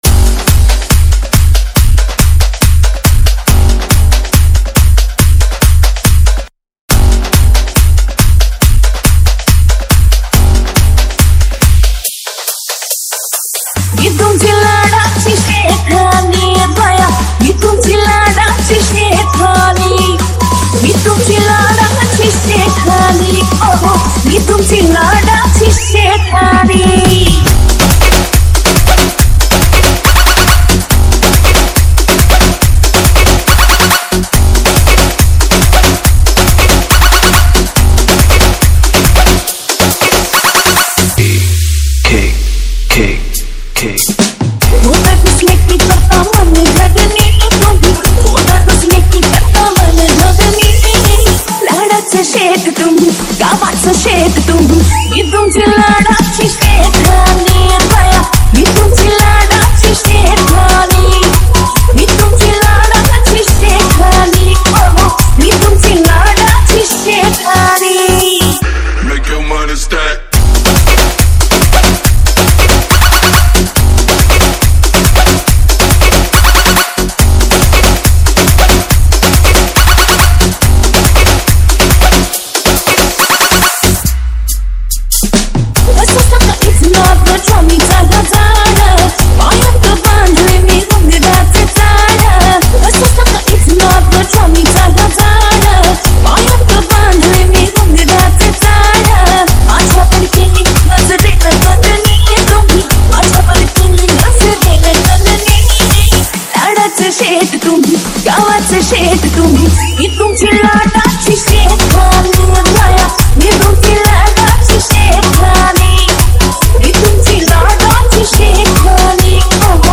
• Category: MARATHI SINGLE